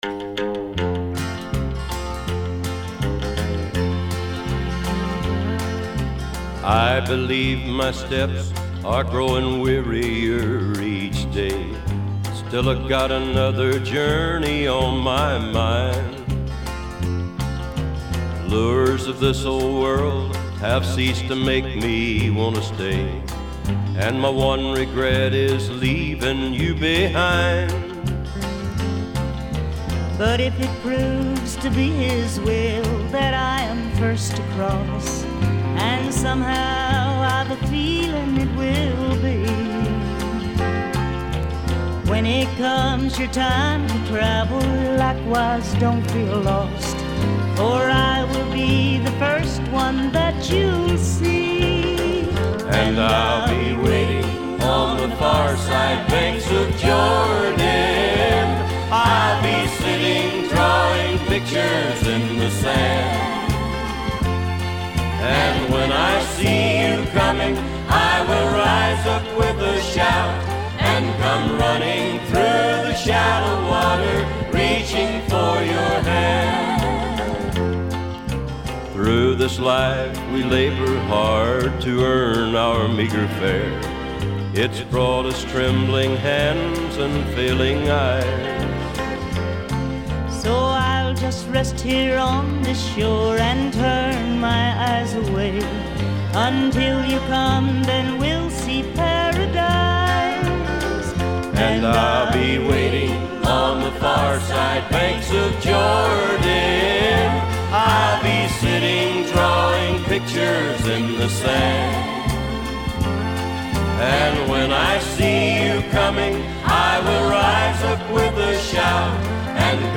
Жанр: Folk, World, & Country, Acoustic, Rock & Roll